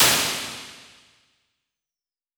Impulsantworten (Samples) einer Hallplatte EMT140
Die Dämpfung und damit die Nachhallzeit wurde variiert, so dass Werte von 0,9 Sekunden bis 3,8 Sekunden zur Verfügung stehen:
Eine einzelne, exemplarische Impulsantwort (Nachhallzeit 1,5 Sekunden) gibt es hier: